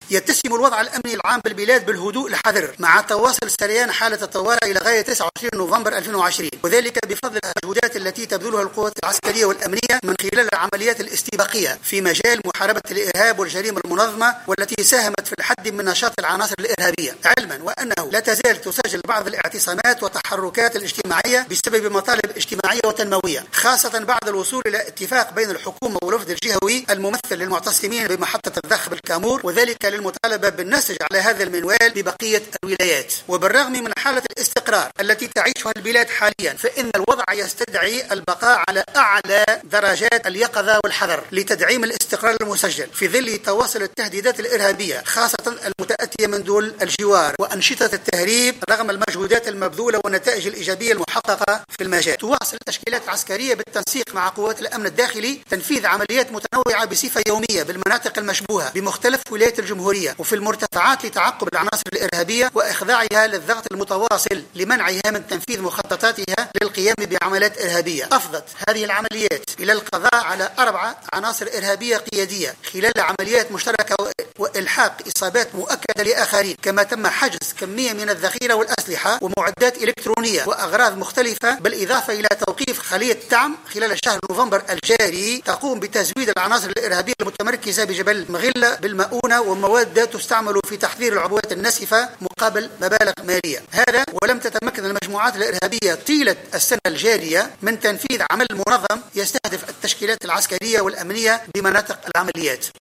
قال وزير الدفاع الوطني ابراهيم البرتاجي خلال الإستماع إليه صباح اليوم الخميس صلب لجنة تنظيم الادارة و شؤون القوات الحاملة للسلاح إن الوضع الأمني بالبلاد يتسم بالهدوء و إن حالة الطوارئ مازالت سارية المفعول إلى غاية 29 نوفمبر2020 .